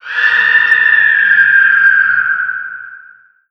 Add ghost sounds.
death.LN50.pc.snd.wav